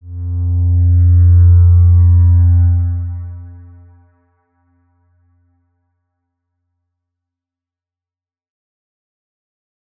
X_Windwistle-F1-mf.wav